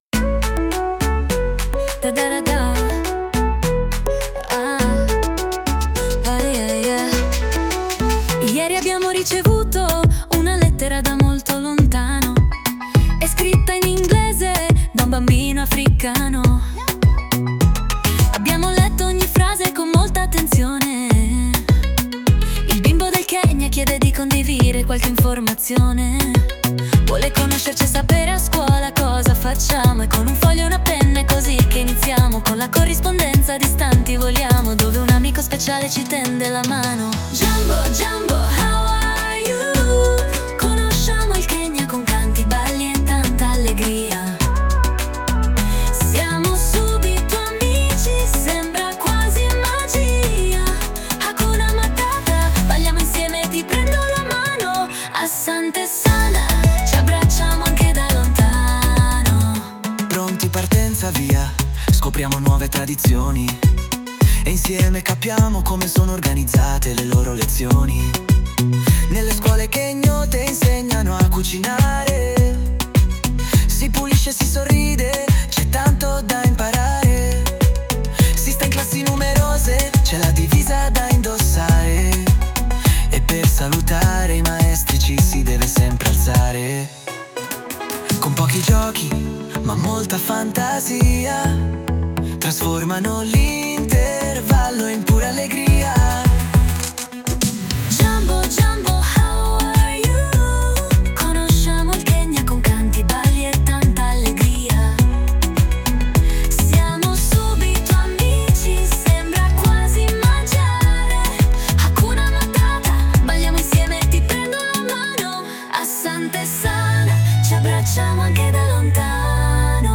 Anche se i bambini non hanno vinto il concorso, hanno scelto di musicare la canzone, cantarla e ballarla durante la giornata dell’intercultura che si è tenuta venerdì 30 maggio 2025 presso il cortile della scuola Primaria di Fornace.